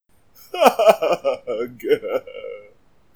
Hug Crying
Tags: podcast comedy